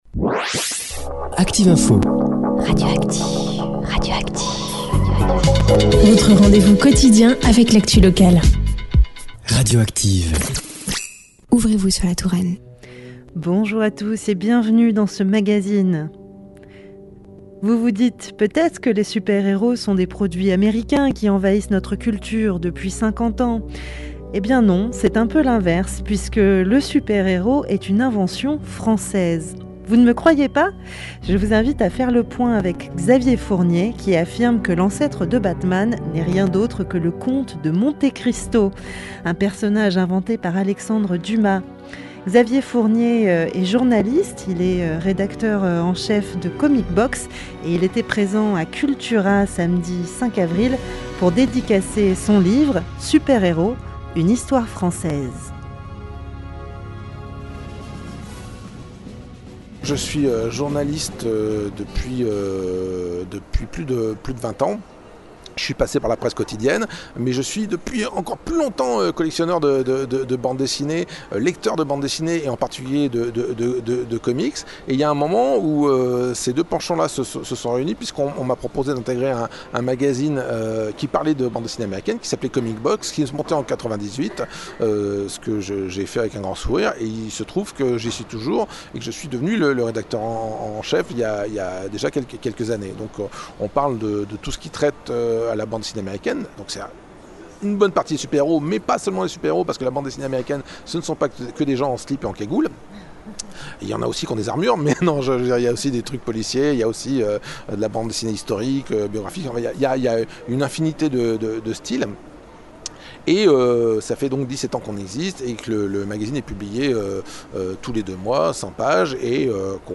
Interview sur Radio Active Fm (07/04/2015)